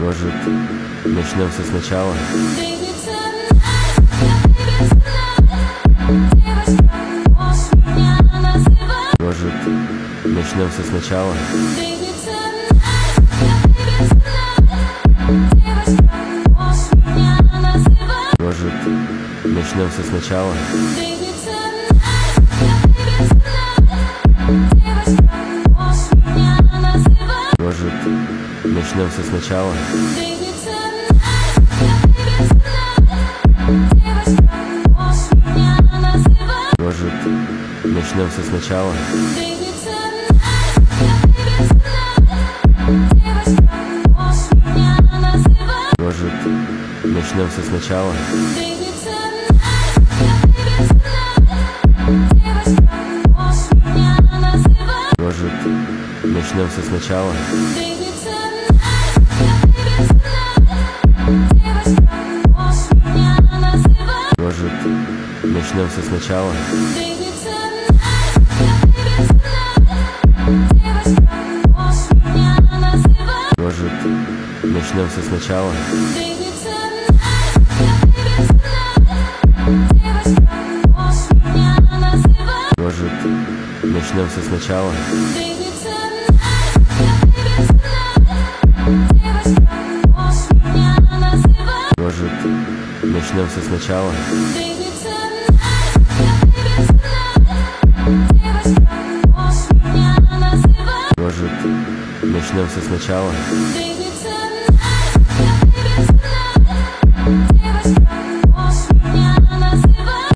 Зарубежная